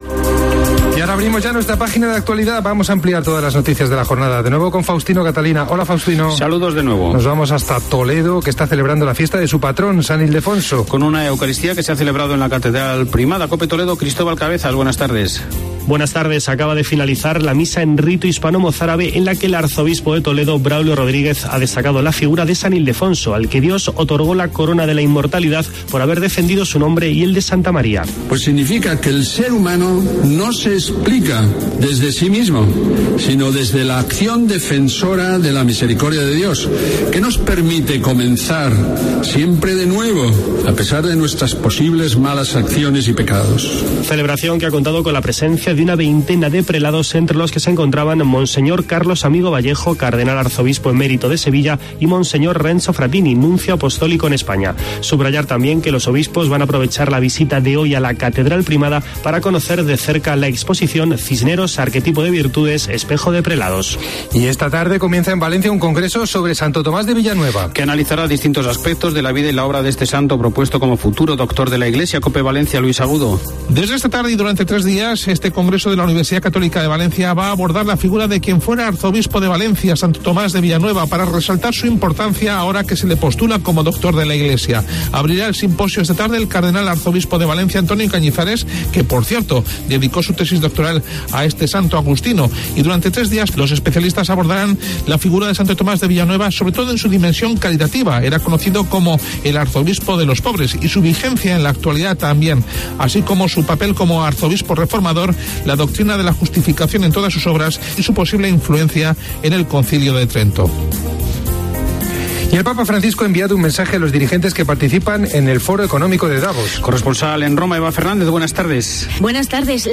El arzobispo de Toledo, Braulio Rodríguez Plaza, ha presidido la Santa Misa en rito hispano-mozárabe en la festividad de San Ildefonso, patrono de la archidiócesis de Toledo.